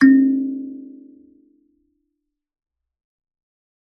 kalimba2_wood-C3-mf.wav